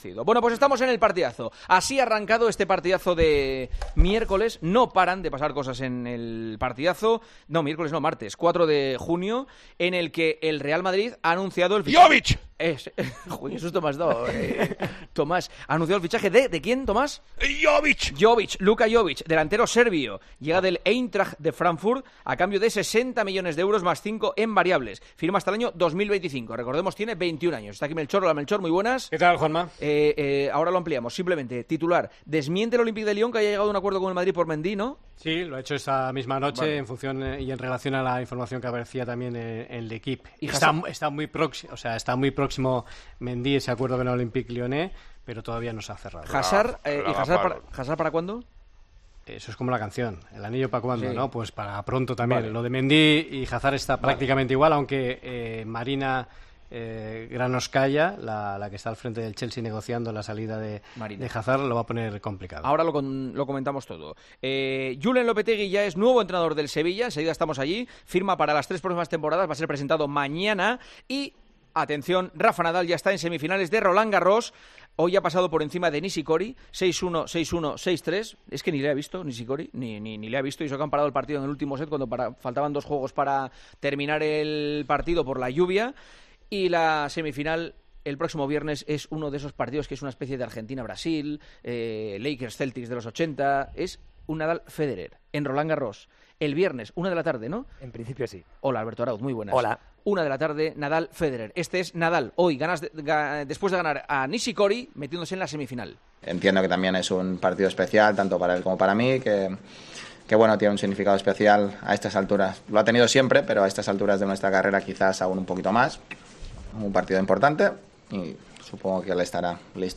Los tertulianos de 'El Partidazo de COPE' analizan el nuevo fichaje del delantero serbio